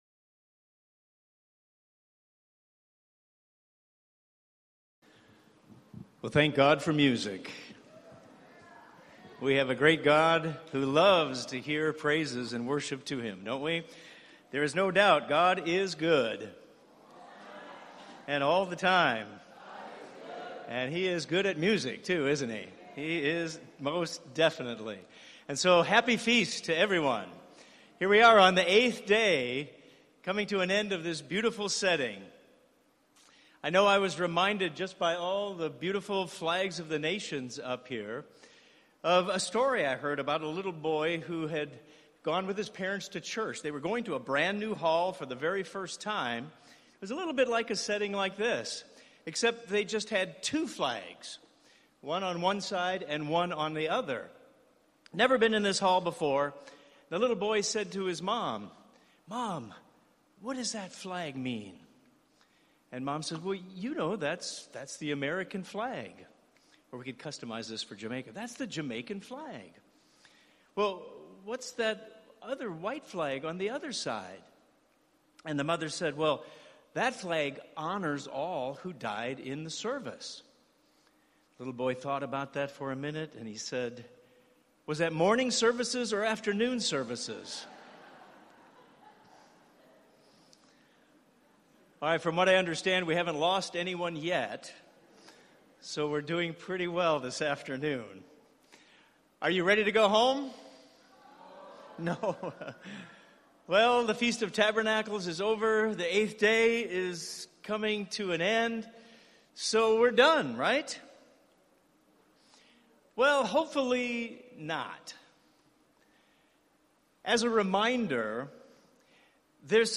This sermon was given at the Montego Bay, Jamaica 2022 Feast site.